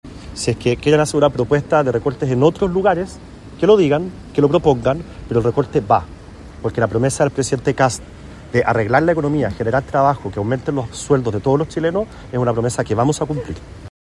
Por su parte, el presidente de la UDI, Guillermo Ramírez, llamó a la oposición a adoptar un rol propositivo y plantear fórmulas que permitan viabilizar el ajuste, asegurando que el objetivo fiscal del Gobierno se mantiene.